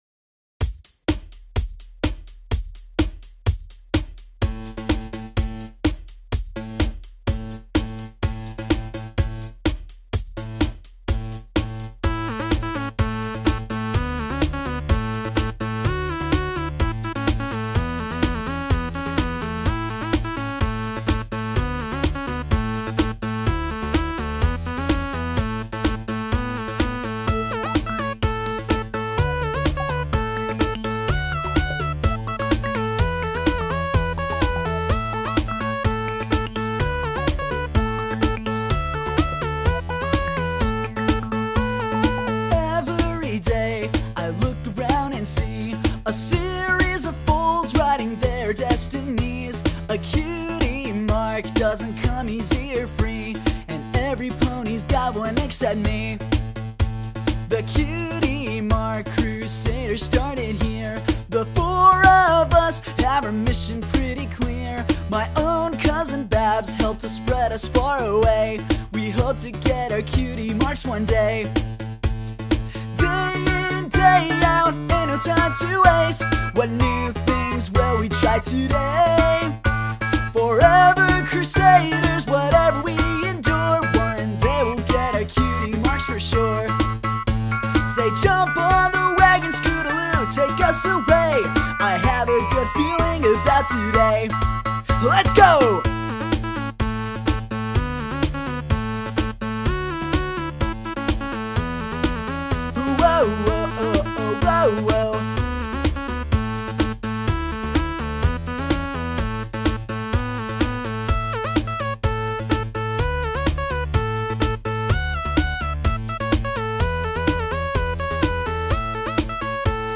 My new fan sew song, this time with vocals.
Vocals were done in one take.